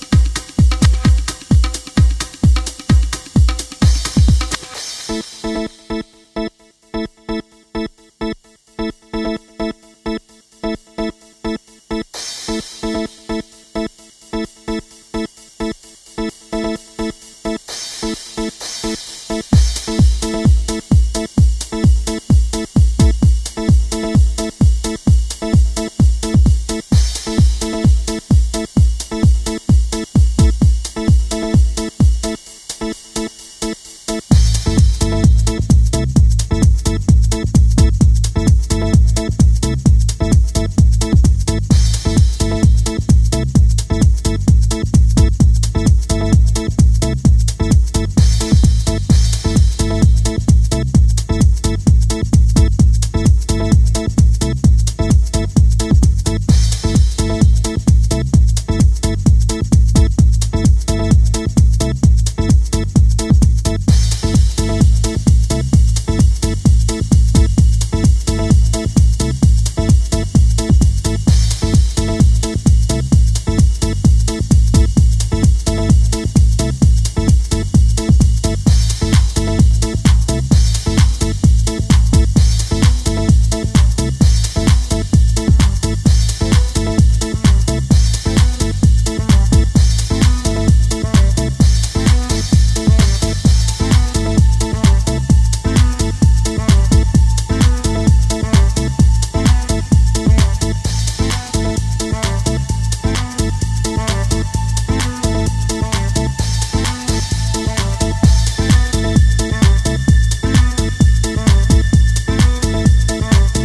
until a boisterous vocal sample enters.